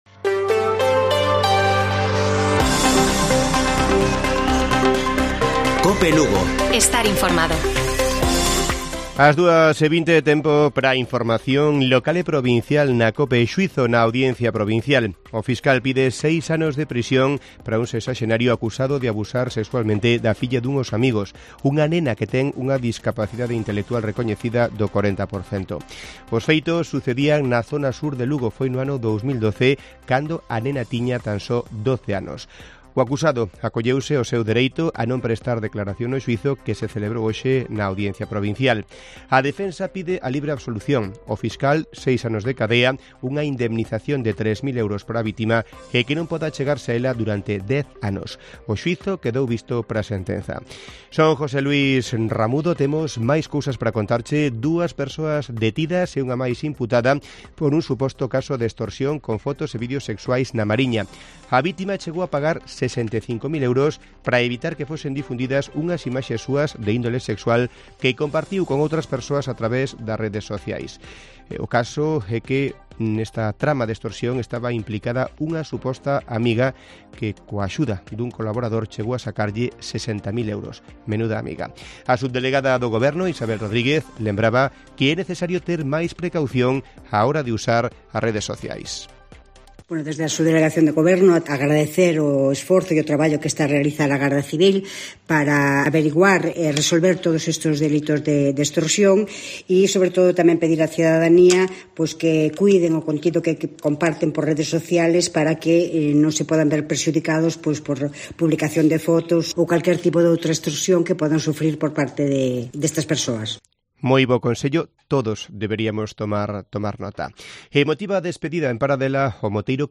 Informativo Mediodía de Cope Lugo. 29 de septiembre. 14:20 horas